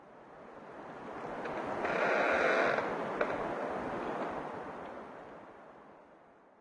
SFX / Ambient / Forest / ambient5.ogg